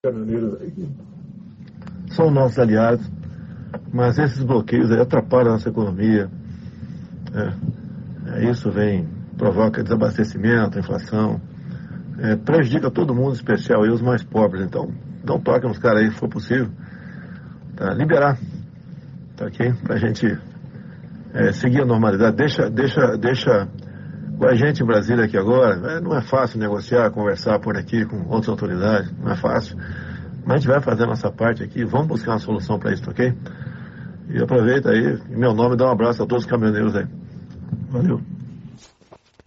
No segundo dia de protestos de caminhoneiros em pelo menos nove estados, o presidente Jair Bolsonaro gravou um áudio pedindo para que os trabalhadores parem a manifestação. Na gravação, que teve a autenticidade confirmada pelo ministro da Infraestrutura Tarcísio Gomes de Freitas, Bolsonaro diz que os bloqueios atrapalham a economia, provocando desabastecimento e inflação.